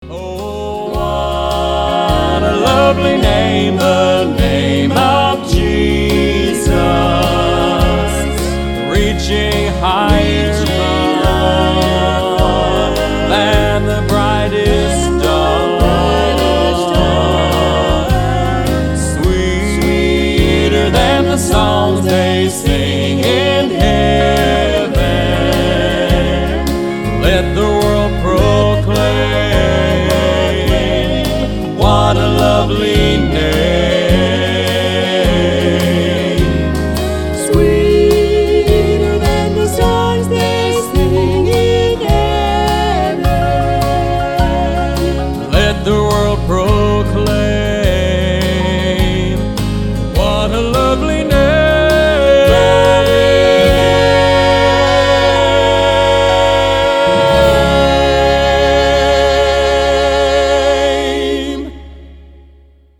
11 Southern Gospel Songs